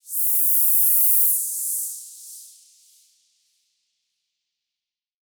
Index of /musicradar/shimmer-and-sparkle-samples/Filtered Noise Hits
SaS_NoiseFilterB-07.wav